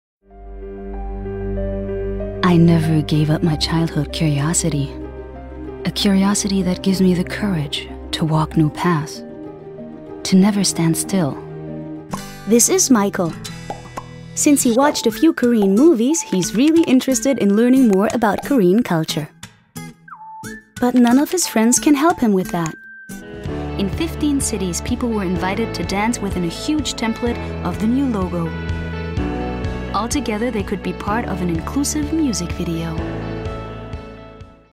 Description: cool, real, fresh, young, engaging, smart, articulate, energetic
English (neutral, Mid Atlantic), English (North America), French, German